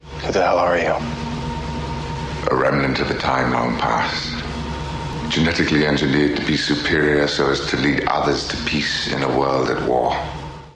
In particular, the widespread and often discussed idea that British or English speech is associated with evil.
Or Benedict Cumberbatch’s super-villain Khan in Star Trek Into Darkness: